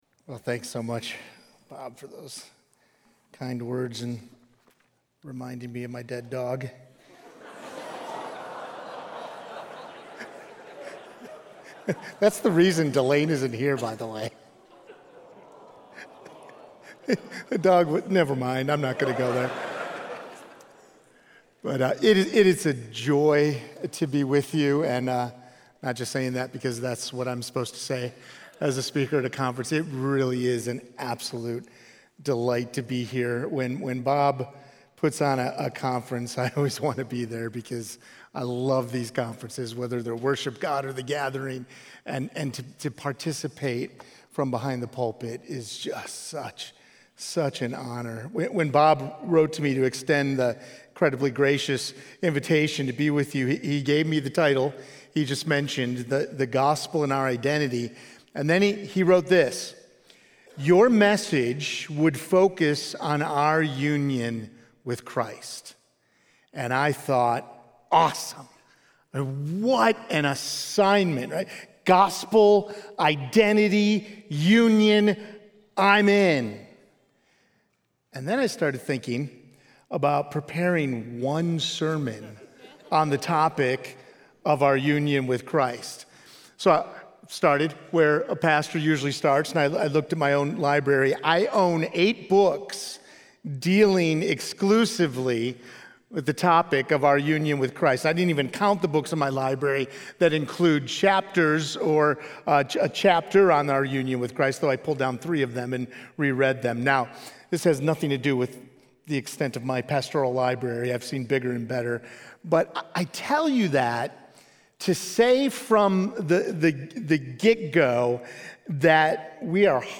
Conference Messages